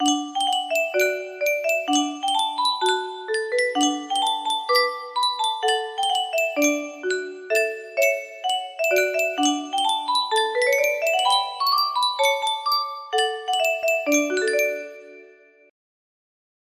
Yunsheng Music Box - Unknown Tune 2378 music box melody
Full range 60